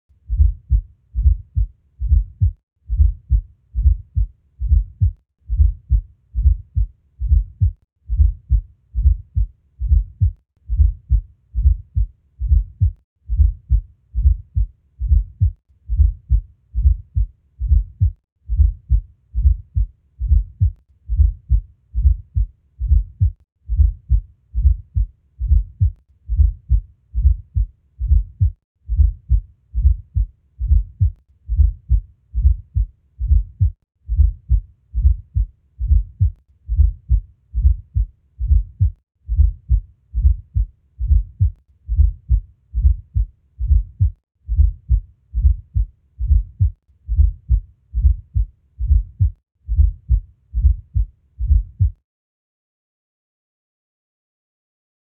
Fourth Heart Sound